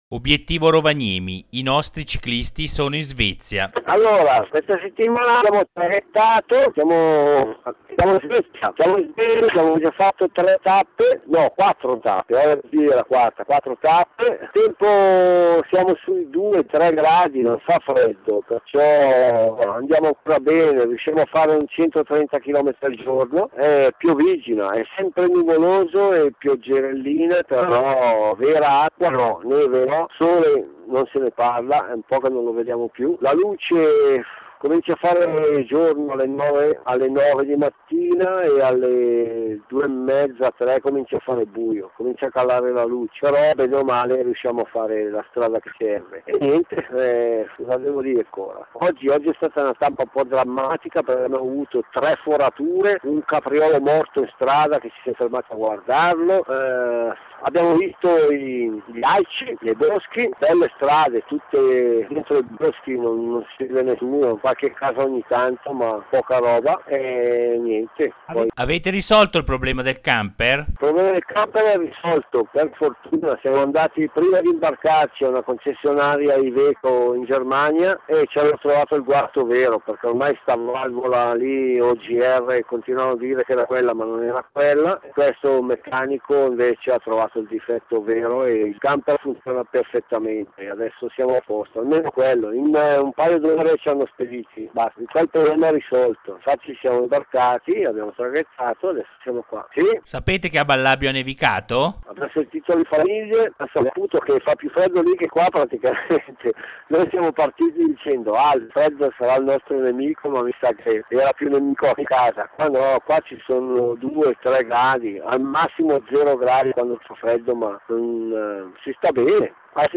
Obiettivo Rovaniemi: Audioracconto della 2a Settimana
30 Novembre 2008 Obiettivo Rovaniemi: Audioracconto della 2a Settimana © Ballabio Online 2008 per un ascolto ottimale si consiglia l'uso di cuffie audio se usi Firefox scarica il seguente plugin [ Clicca qui ]